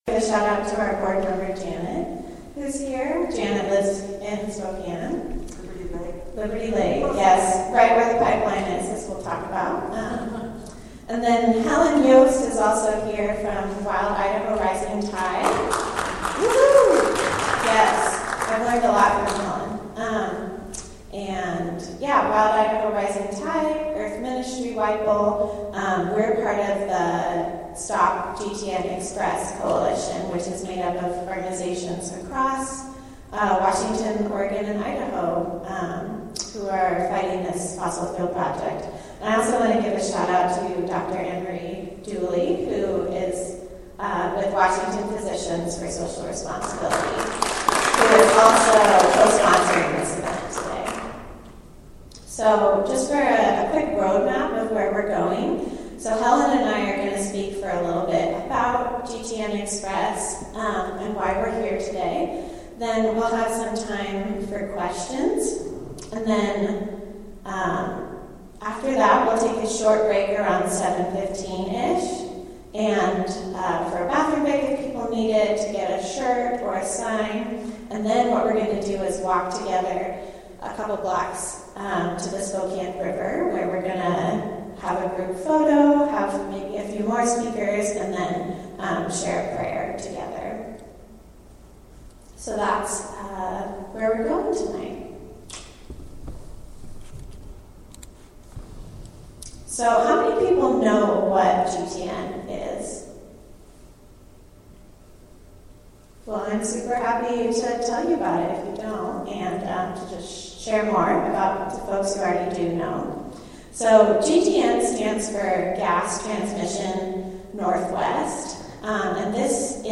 The Wednesday, April 19, 2023, Climate Justice Forum radio program, produced by regional, climate activists collective Wild Idaho Rising Tide (WIRT), features faith and climate leaders talking about Northwest fracked gas pipeline expansion and its resistance at the April 12 Spokane Community GTN Xpress Teach-In. We also share news, music, and reflections on anti-oil train graffiti and upcoming Earth Day gatherings in north Idaho and Washington, supporting and opposing views on Lake Pend Oreille deforestation collaboration, an Idaho town hall meeting on recently legislated state oil and gas rules, and a postponed federal decision and faith organizations letter requesting regulator rejection of GTN Xpress.
spokane-community-gtn-xpress-teach-in-4-12-23.mp3